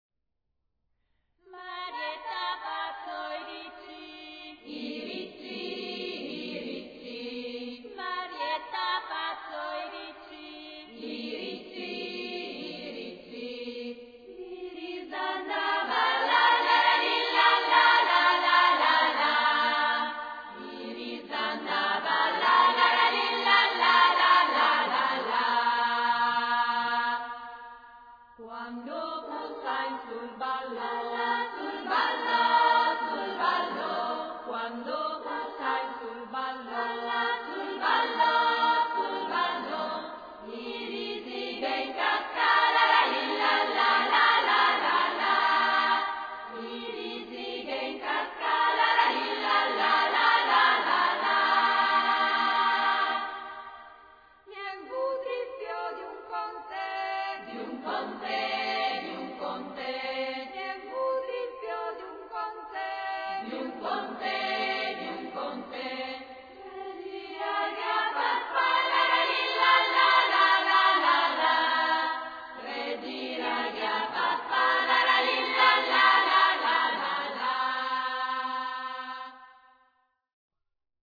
[ voci femminili ]